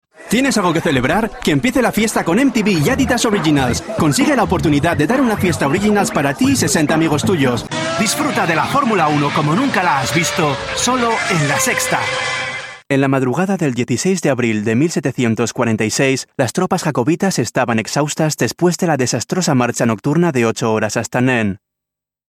Commercial 2